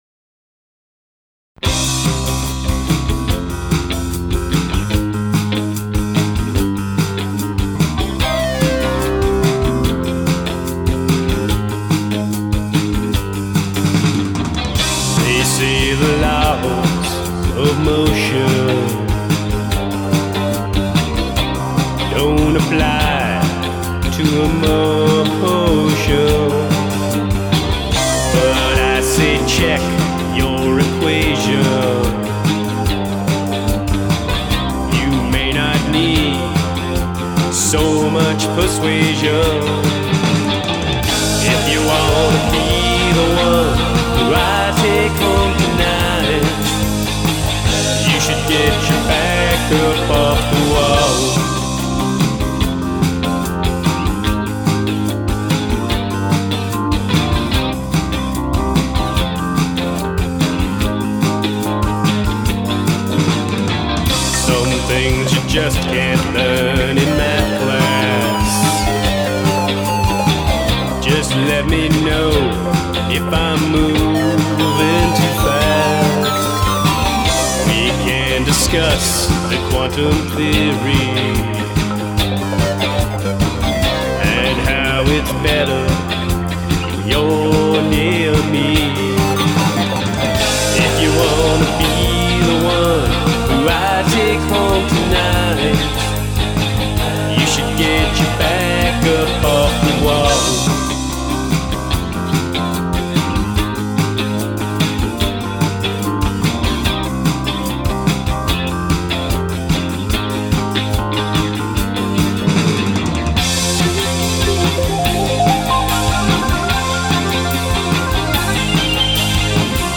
A dance song inspired by a biography of Albert Einstein.